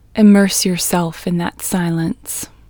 QUIETNESS Female English 13
Quietness-Female-13-1.mp3